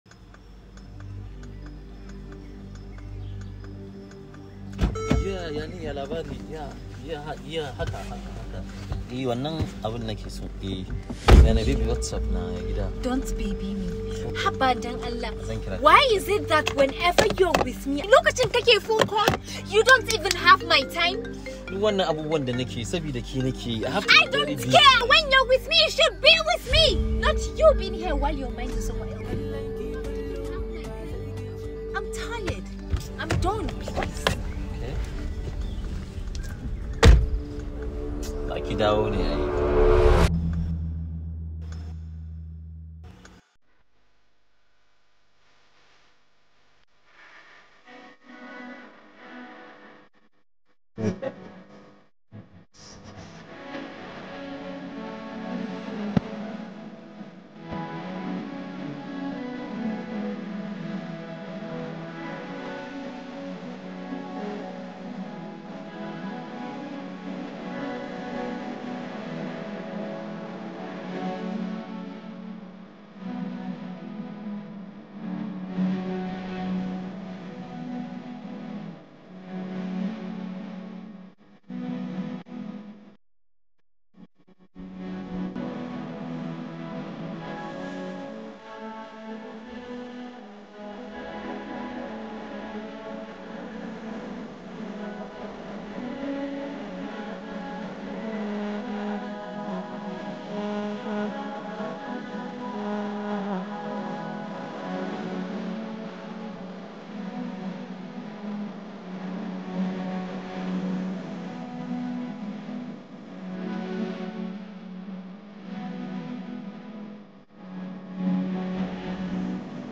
a very interesting Arewa Tune
energetic ans soothing melodies